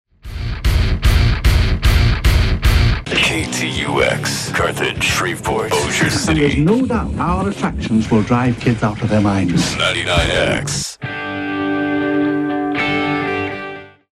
KTUX Top of the Hour Audio: